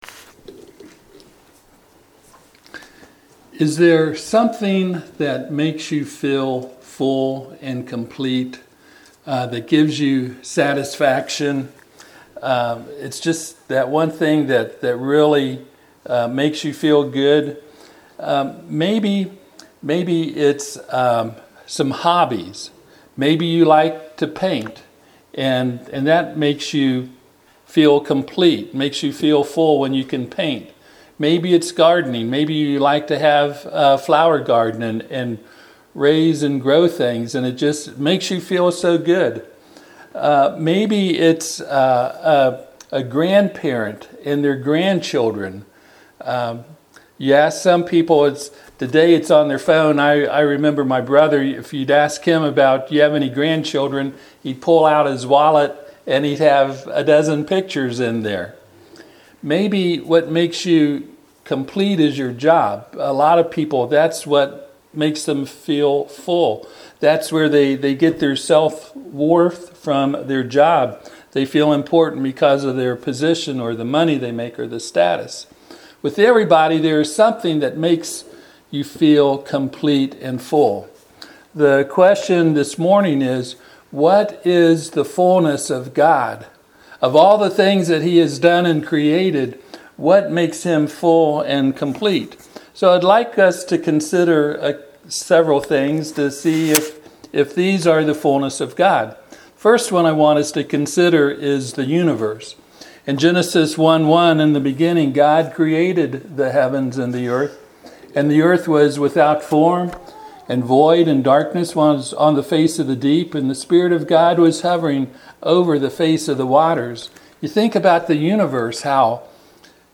Preacher
Ephesians 1:22-23 Service Type: Sunday AM « Revelation